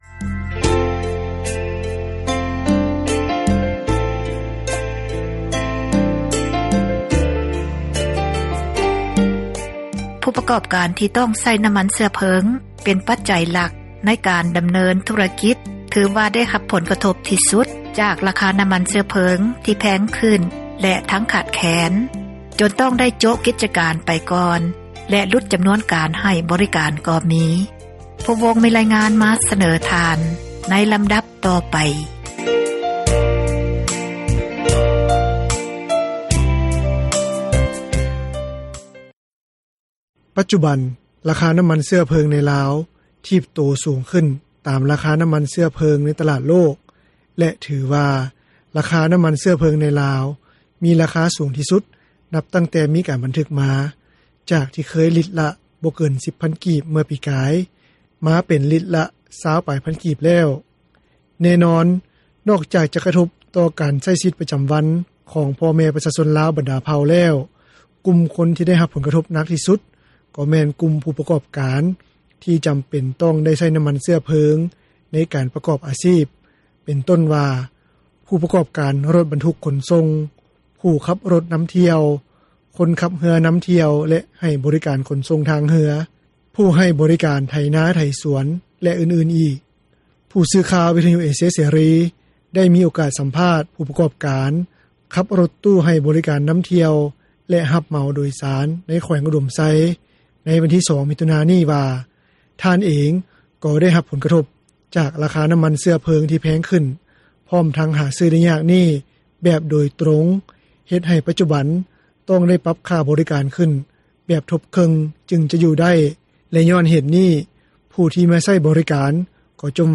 ຜູ້ສື່ຂ່າວ ວິທຍຸເອເຊັຽເສຣີ ໄດ້ມີໂອກາດສັມພາດ ຜູ້ປະກອບການ ຂັບຣົຖຕູ້ ໃຫ້ບໍຣິການນຳທ່ຽວ ແລະ ຮັບເໝົາໂດຍສານ ໃນແຂວງອຸດົມໄຊ ໃນວັນທີ 2 ມິຖຸນາ ນີ້ວ່າ ທ່ານເອງ ກໍໄດ້ຮັບຜົລກະທົບ ຈາກລາຄານ້ຳມັນເຊື້ອເພີງ ທີ່ແພງຂຶ້ນ ພ້ອມທັງຫາຊື້ໄດ້ຍາກນີ້ ແບບໂດຍຕຣົງ ເຮັດໃຫ້ປັດຈຸບັນ ຕ້ອງໄດ້ປັບຄ່າບໍຣິການຂຶ້ນ ແບບທົບເຄິ່ງ ຈຶ່ງຈະຢູ່ໄດ້ ແລະ ຍ້ອນເຫດນີ້ ຜູ້ທີ່ມາໃຊ້ບໍຣິການ ກໍຈົ່ມວ່າແພງ, ແຕ່ກໍໄດ້ພະຍາຍາມອະທິບາຍ ແລະ ລູກຄ້າກໍຫຼຸດລົງຫຼາຍ ໃນໄລຍະນີ້.